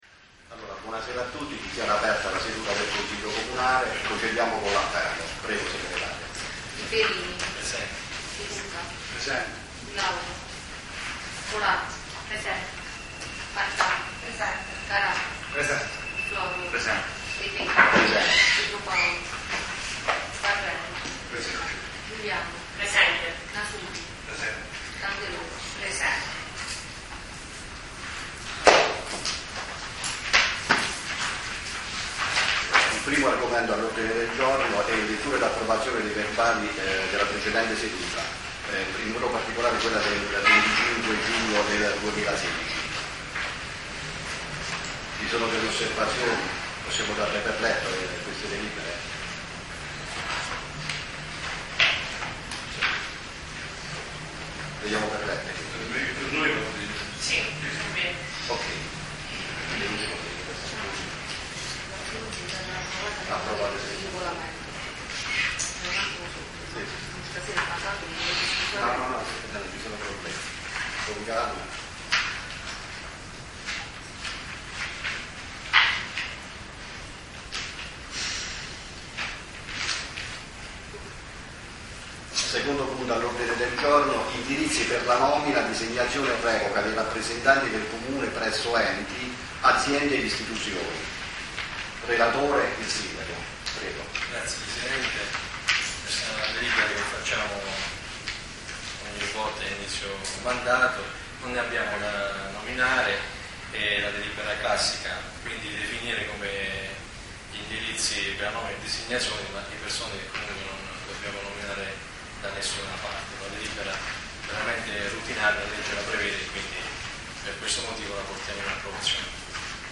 Ascolta il Consiglio Comunale del 28 Luglio 2016